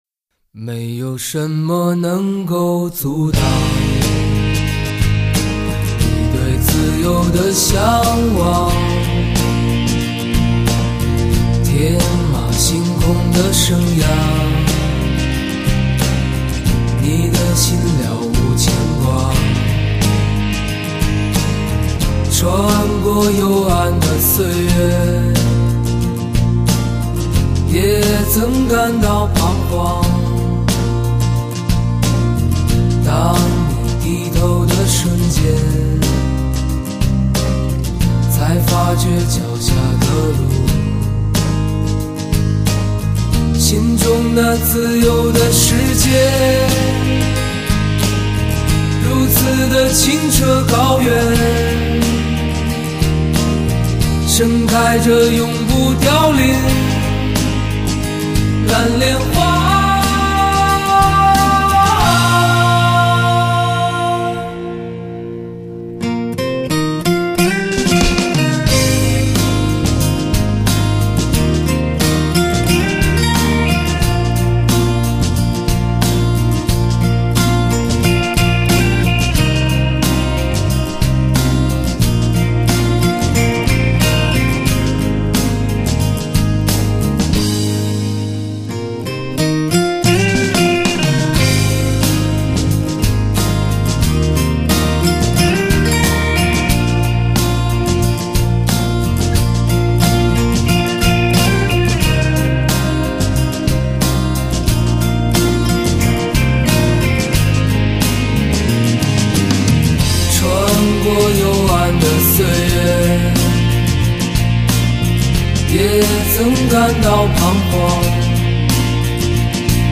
精选汽车专用无损音质
发烧老情歌 纯音乐
极致发烧HI-FI人声测试碟